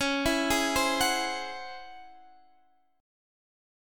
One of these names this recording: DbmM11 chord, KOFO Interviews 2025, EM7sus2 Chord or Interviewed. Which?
DbmM11 chord